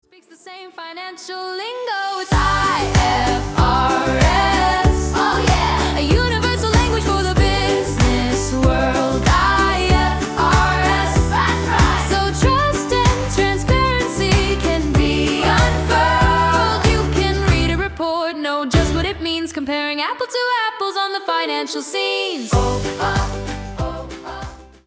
Accounting Karaoke System